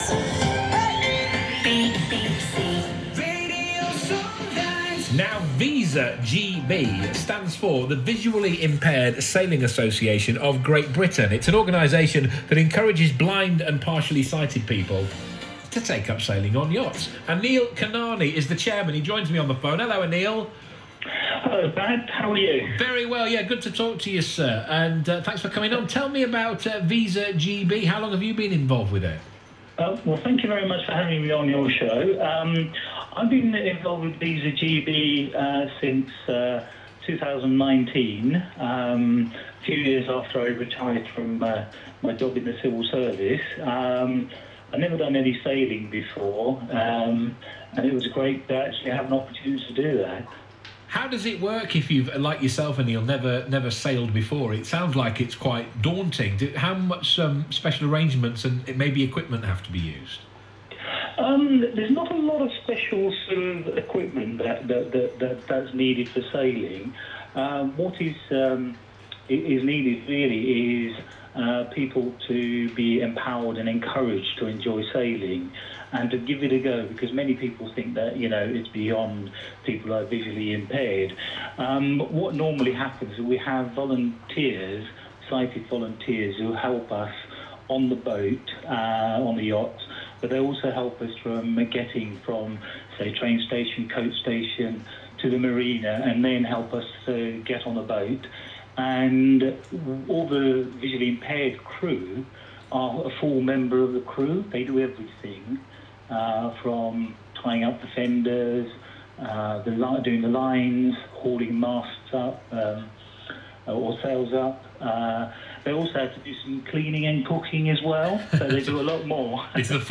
Radio Solent in conversation with our Chairman